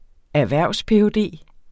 Udtale [ æɐ̯ˈvæɐ̯ˀwspehɔˌdeˀ ]